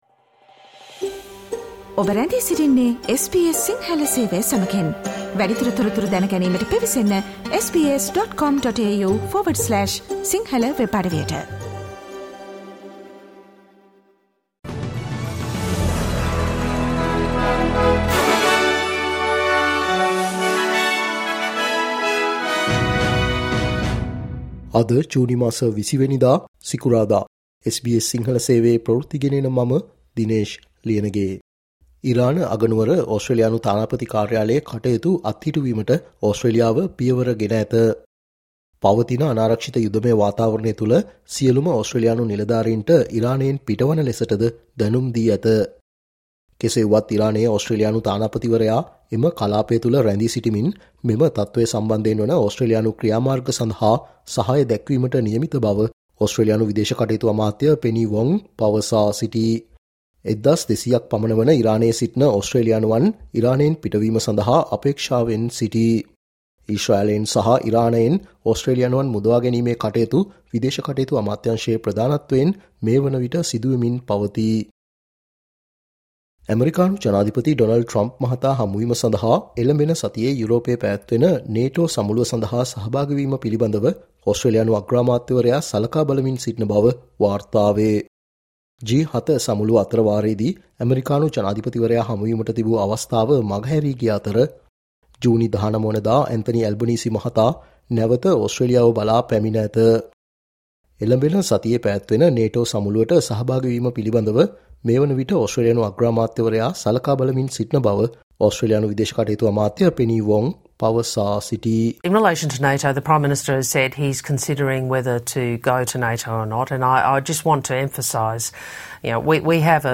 ඕස්ට්‍රේලියාවේ පුවත් සිංහලෙන් දැනගන්න, ජූනි මස 20 වන දා SBS සිංහල Newsflashවලට සවන් දෙන්න